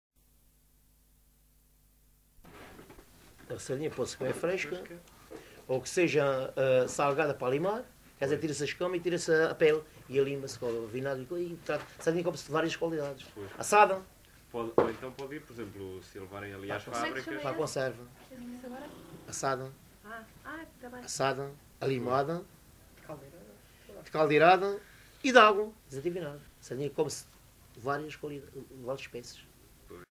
LocalidadeAlvor (Portimão, Faro)